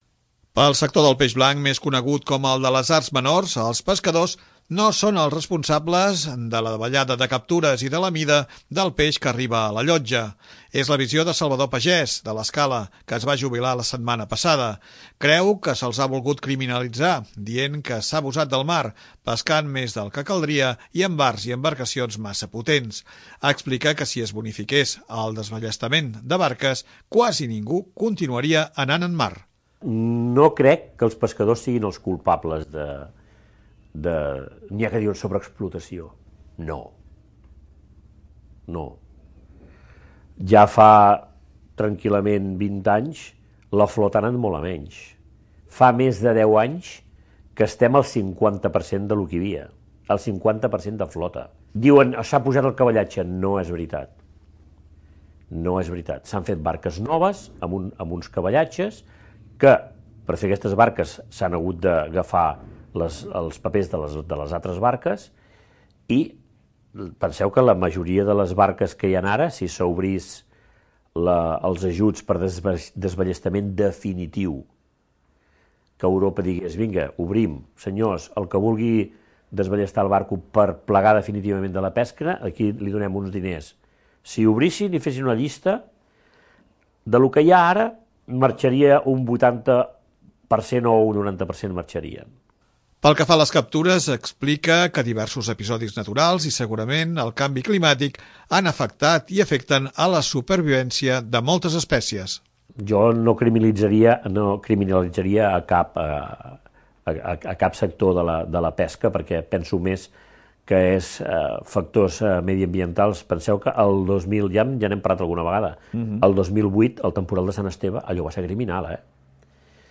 Són alguns retalls de l'entrevista de divendres passat al programa Parlem de Canal 10 Empordà.